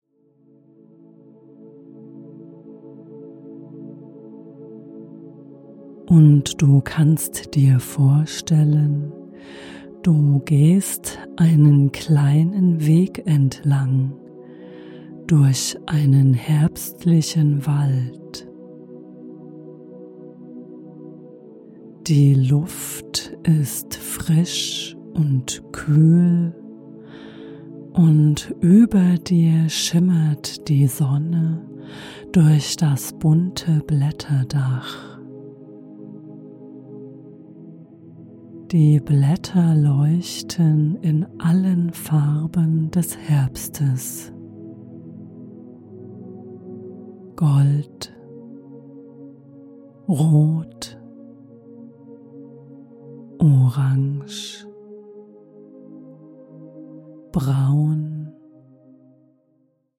Die einfühlsame Anleitung unterstützt dich dabei, Schritt für Schritt loszulassen und dich von den Eindrücken des Herbstes tragen zu lassen. Geräusche, Bilder und Empfindungen schaffen einen Raum, in dem du innere Ruhe findest, Stress hinter dir lässt und deine Gedanken leichter werden dürfen.
Sie wirkt beruhigend, stärkend und ist voller leiser Schönheit.